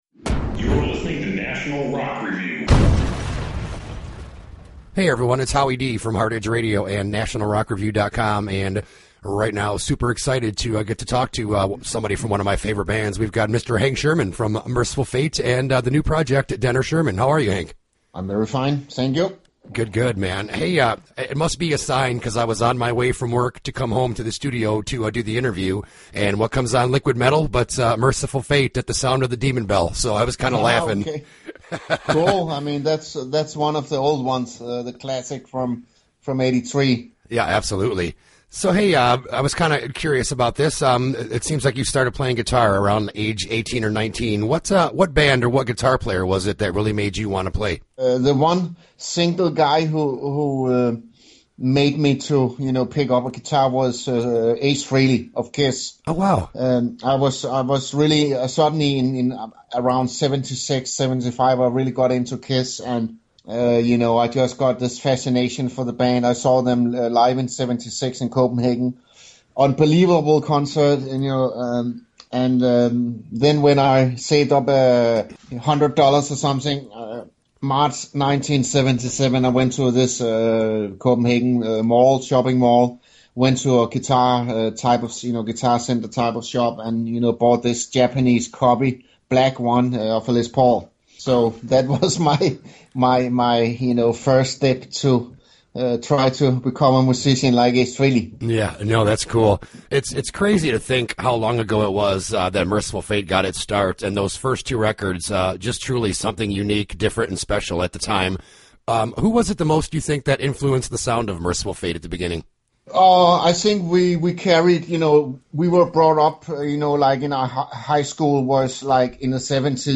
The interview with Hank Shermann of Mercyful Fate and Denner/Shermann: